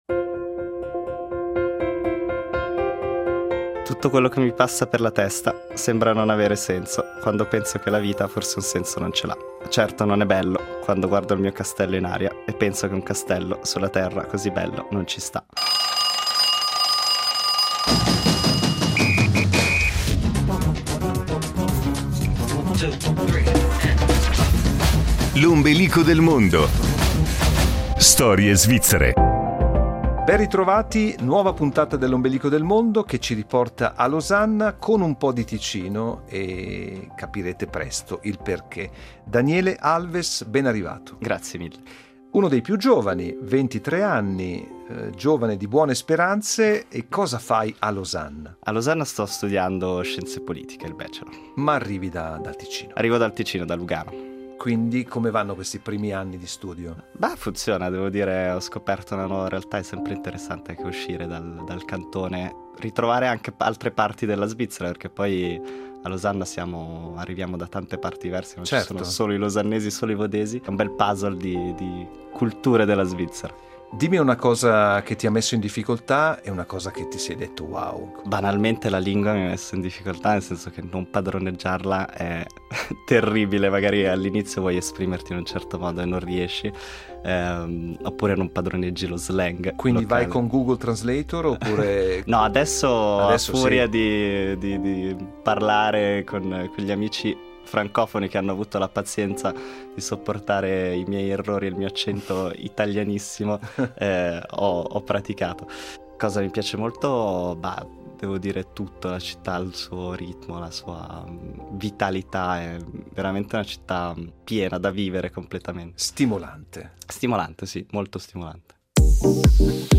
La sua scelta musicale ha privilegiato un brano di Brunori Sas.